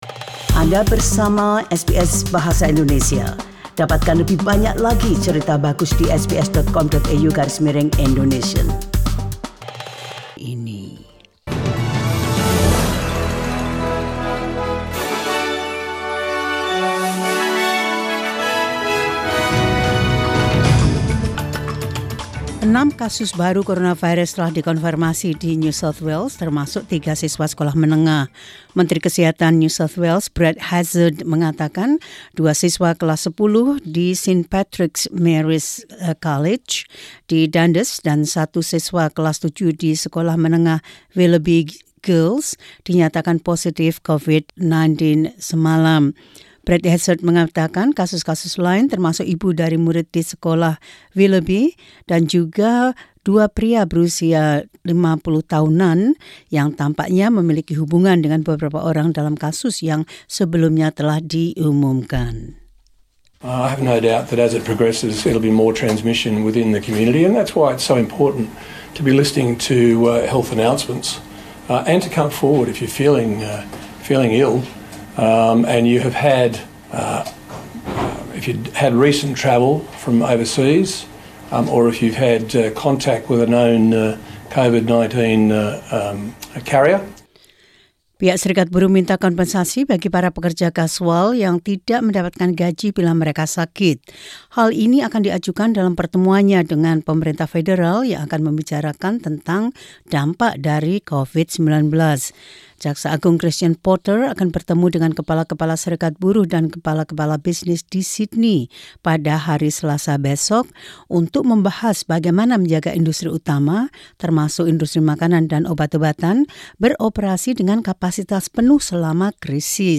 SBS Radio News in Indonesian 9 Mar 2020.